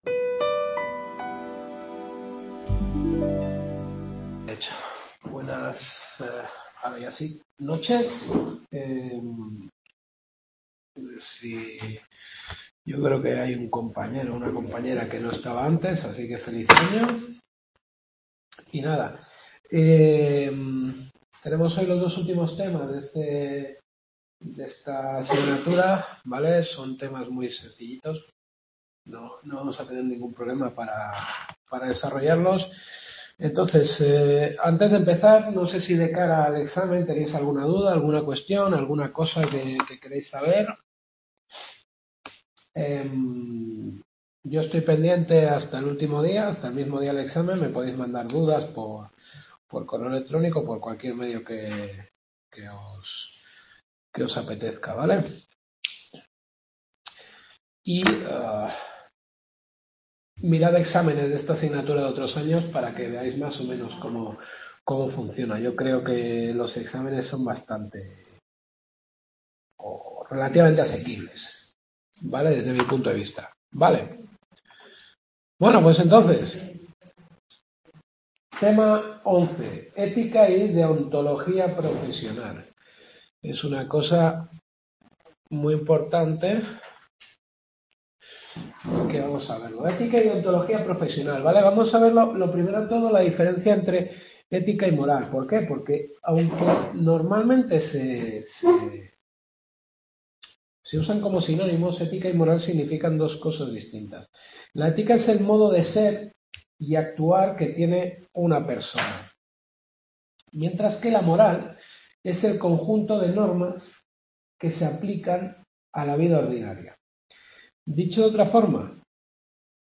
Sexta y última clase.